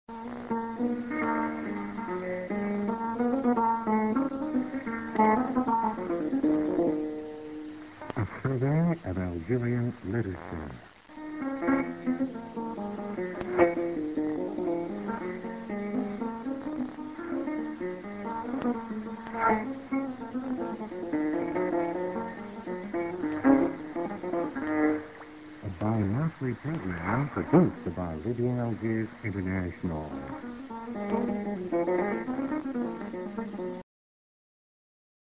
Mid. music->ID(man)->music